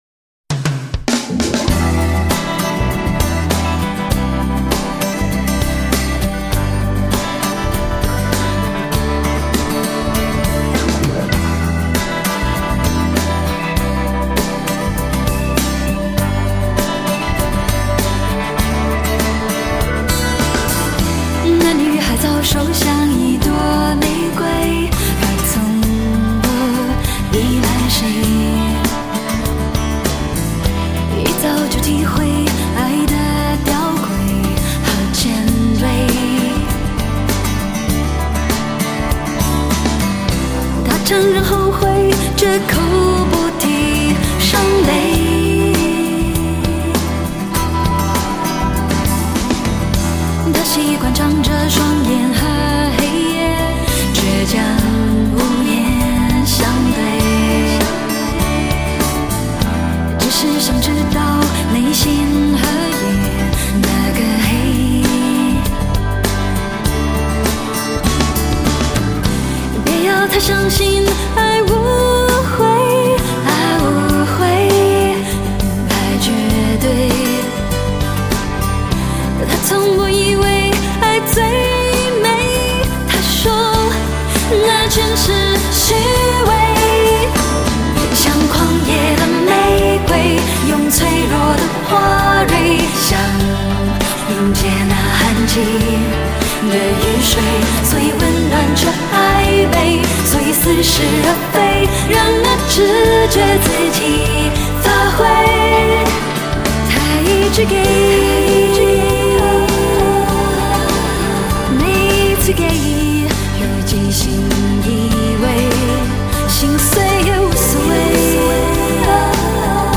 唱片类型：流行经典